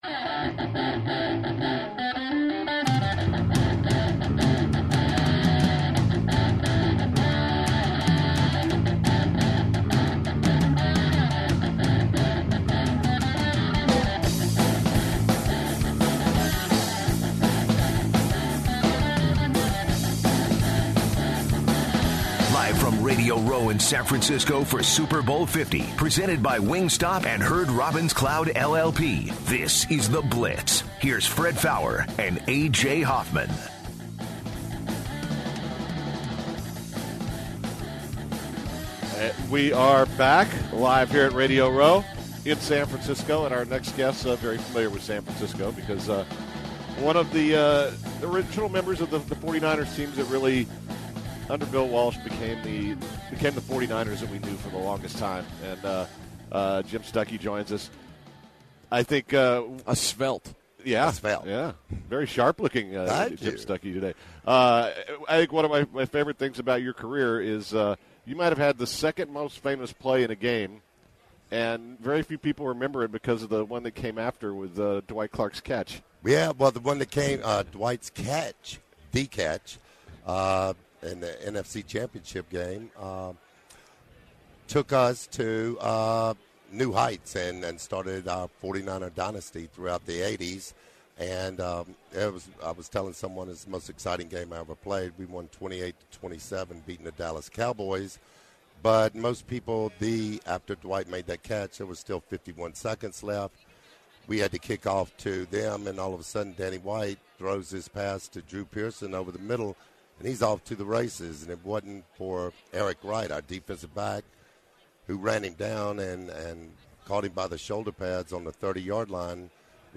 The third hour of the Blitz started off with an interview